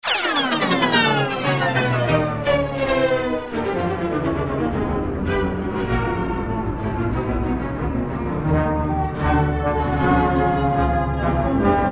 In the time domain, odist shows itself to be a continuous tempo change, a special type of tempo glissando.